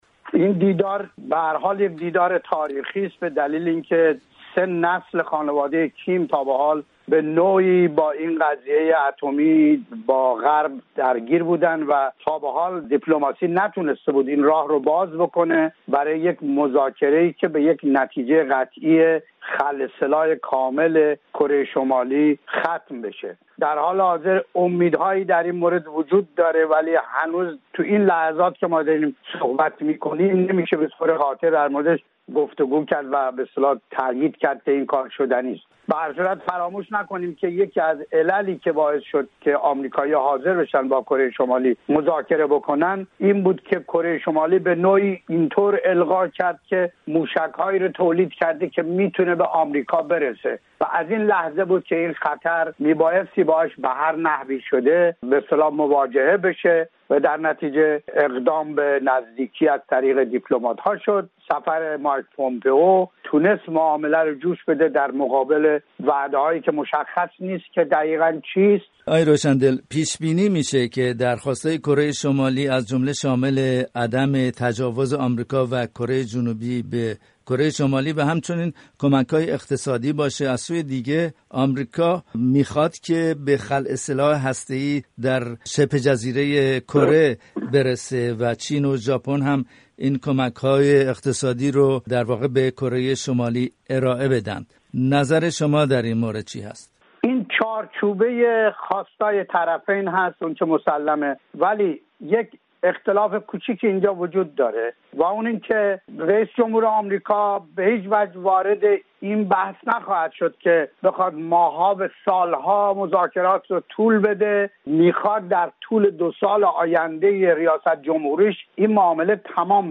تحلیلگر امور بین‌الملل